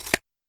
Opus versions of the sound pack.